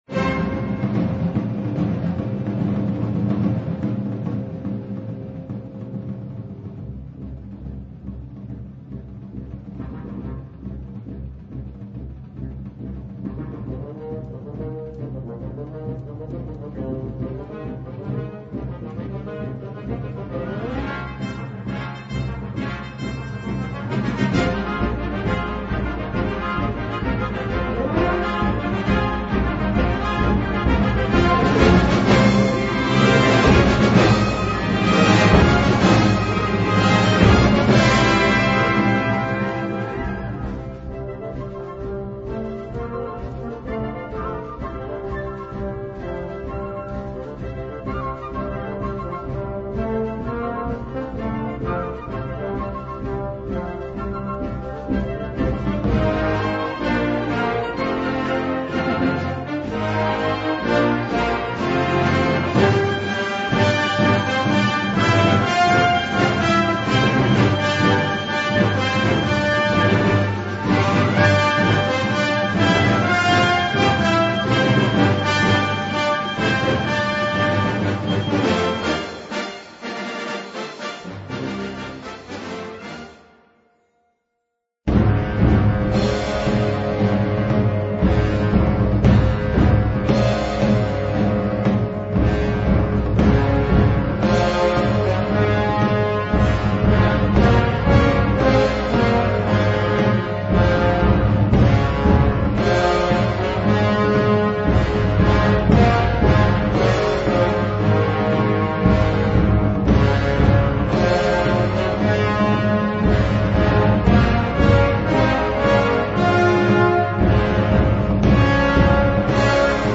Unterkategorie Suite
Besetzung Ha (Blasorchester)